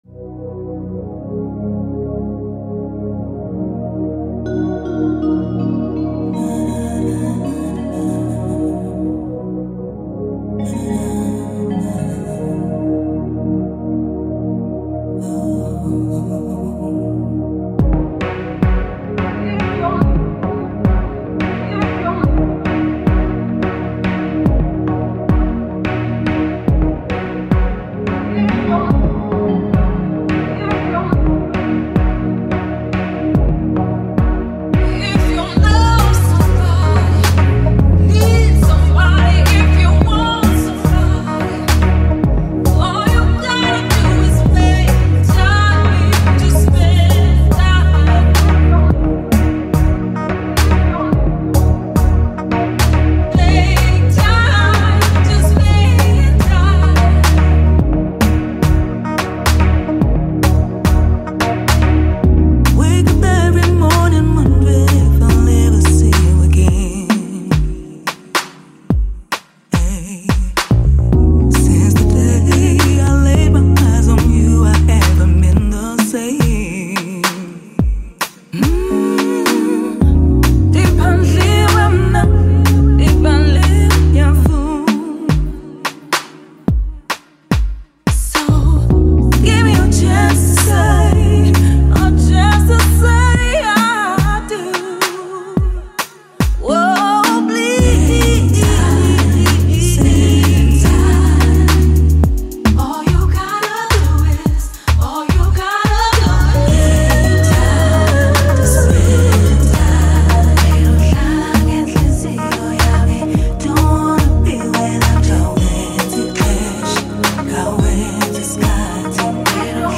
soulful record